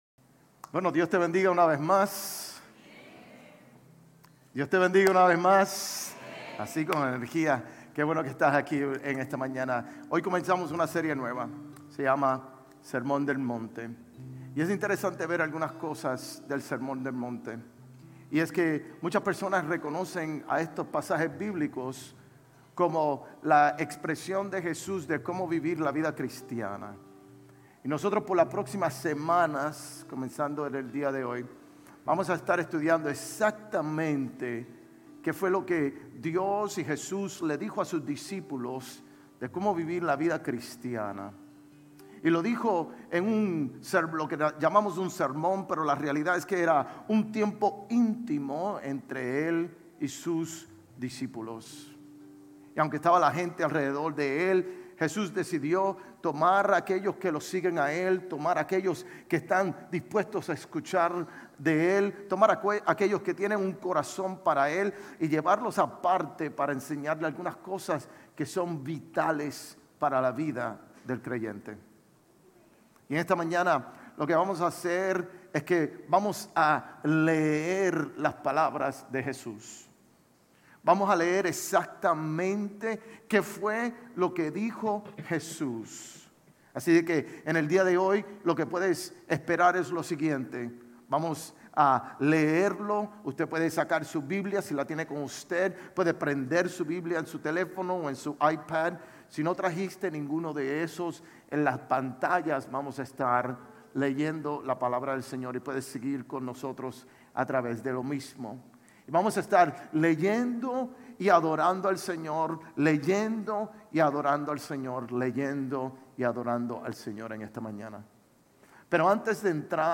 Sermones Grace Español Ene 19 - Grace Español Campus Jan 05 2025 | 00:29:24 1x 00:00 / 00:29:24 Subscribe Share RSS Feed Share Link Embed